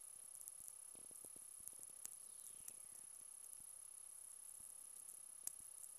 Forest, Virginia